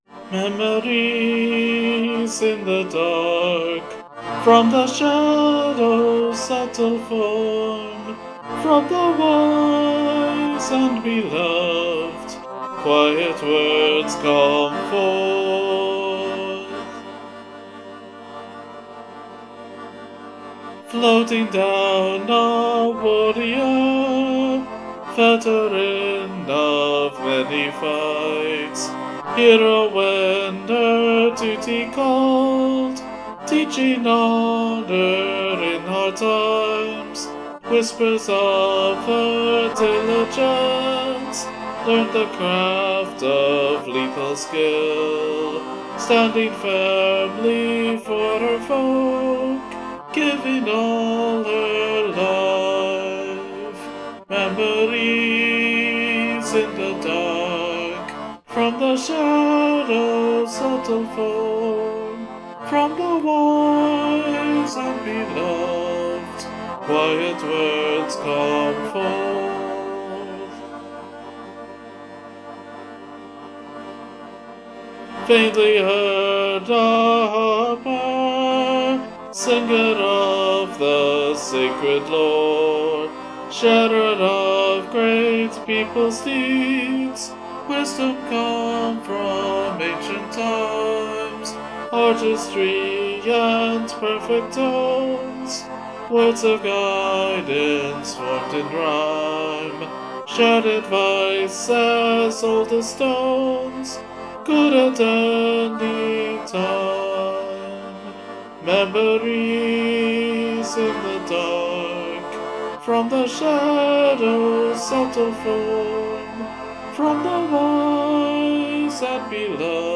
For something like this, rhythm can be a bit approximate to begin with, but the rhythmic pattern really throws things off with the dotted quarters that turn the rhythm into something that floats a bit. How that's happening, in a nutshell, is breaking the standard even rhythmic pattern into one of 2+3+3, with one short beat (the eighth notes) followed by 2 long beats (either 2 dotted quarters or 1 dotted half). This doesn't even match a fairly common drumming pattern of 3+3+2, so it feels unnatural, which is of course the intent.